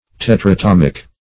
Search Result for " tetratomic" : The Collaborative International Dictionary of English v.0.48: Tetratomic \Tet`ra*tom"ic\, a. [Tetra- + atomic.]